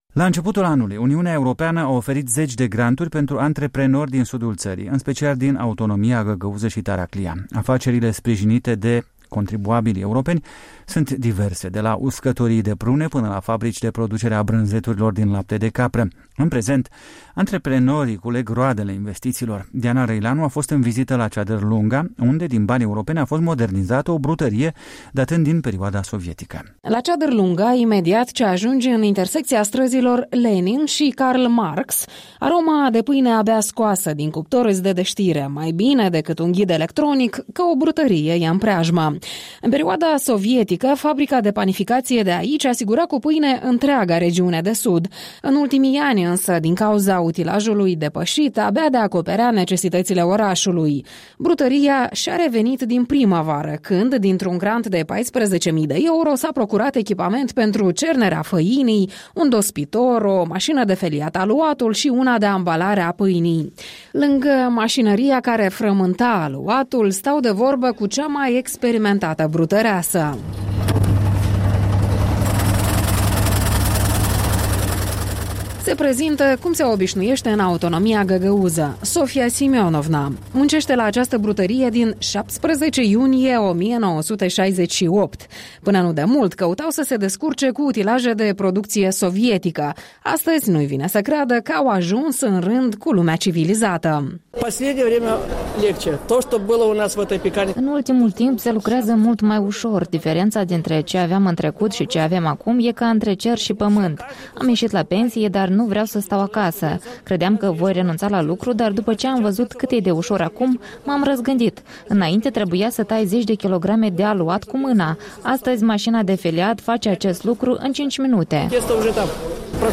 Un reportaj despre modernizarea unei brutării în sudul țării cu ajutorul fondurilor europene.